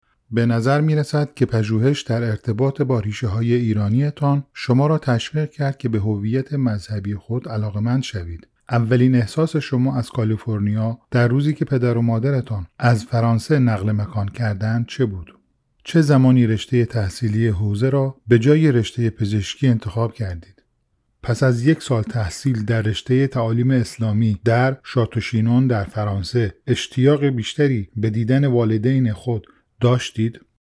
Male
Adult
Educational